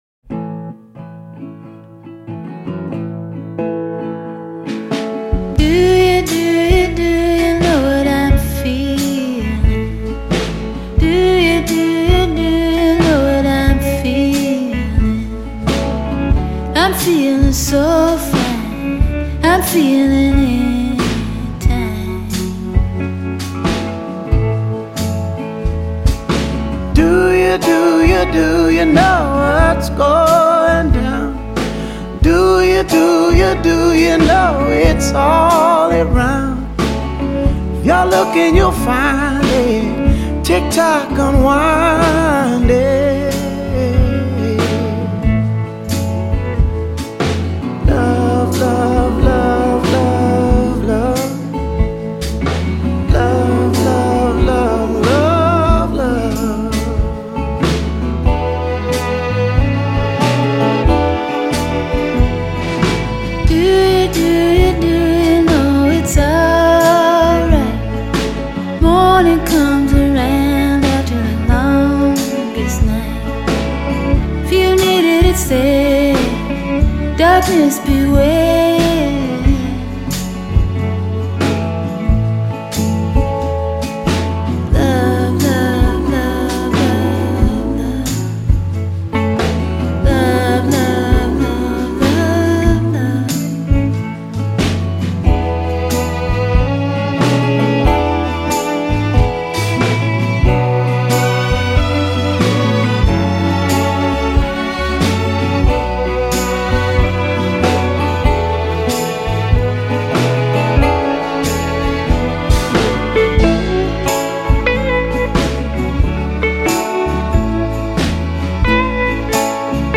Любителям блюза, кантри, фолка в расслабляющем варианте
Певица и автор песен